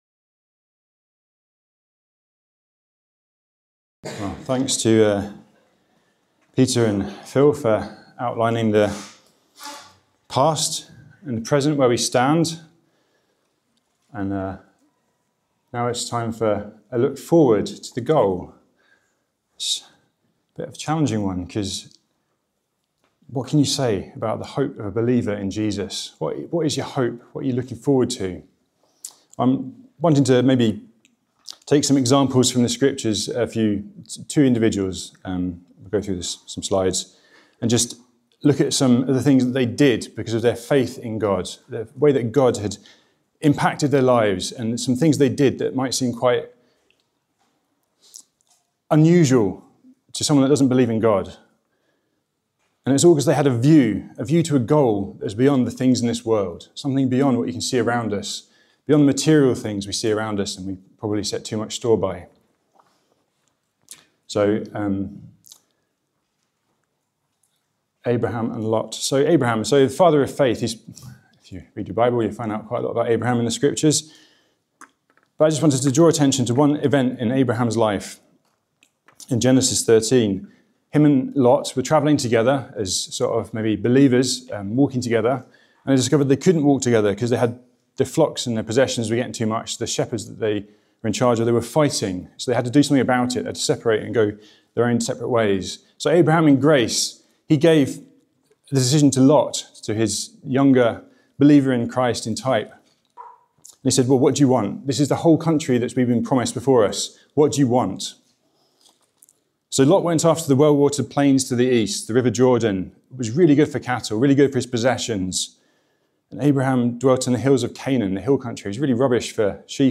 This talk from Refresh 2026 focuses on "Henceforth"—looking onward to the ultimate goal. After reflecting on God’s past blessings and our present walk, the speaker encourages us to set our eyes on the Lord Jesus. Using examples like Abraham, who sought the eternal city, and Daniel, who prayed with hope in exile, we are reminded that our goals are set on the Lord Jesus.